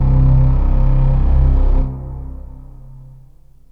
STRINGS 0001.wav